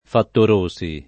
[ fattor 1S i ]